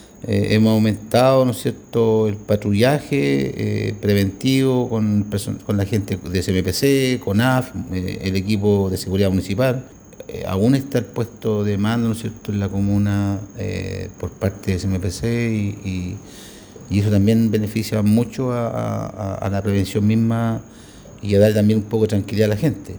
Por su parte, el alcalde de Laja, Vladimir Fica, indicó que también se llevarán a cabo diversas acciones preventivas para enfrentar eventuales emergencias.